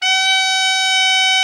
Index of /90_sSampleCDs/Giga Samples Collection/Sax/ALTO SAX